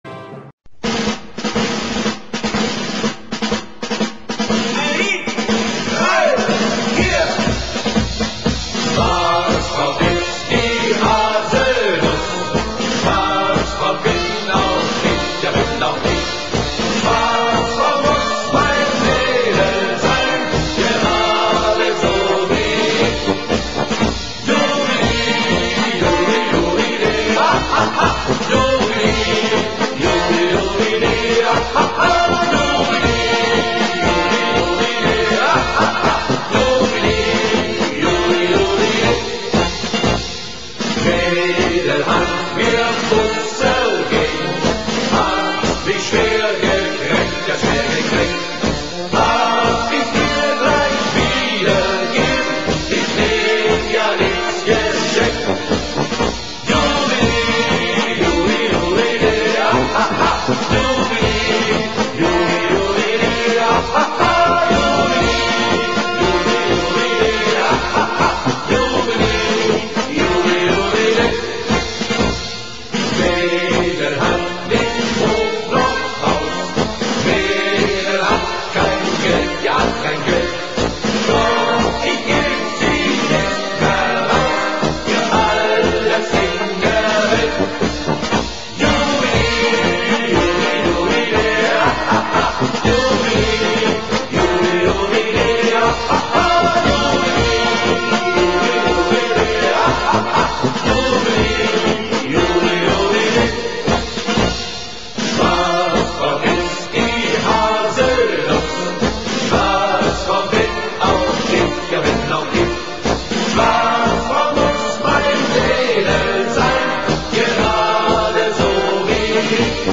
10 German Marching Songs